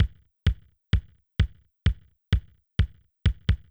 CLF Beat - Mix 7.wav